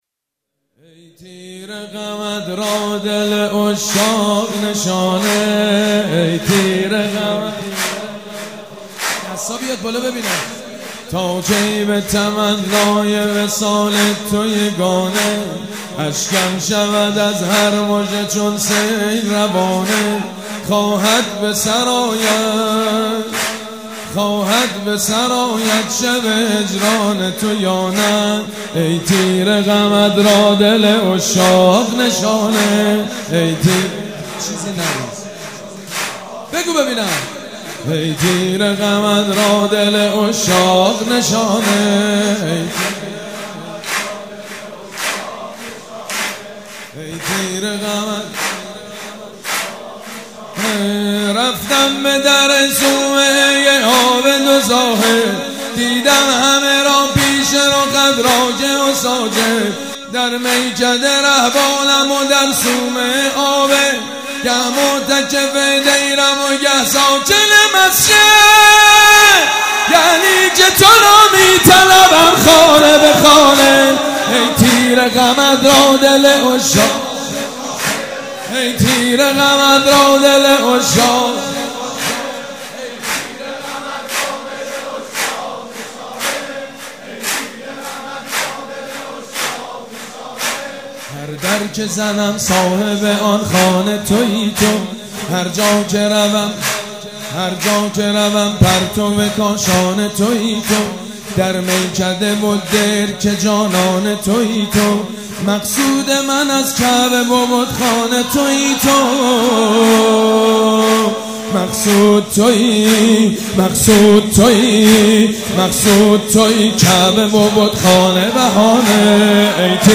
سرود: ای تیر غمت را دل عشاق نشانه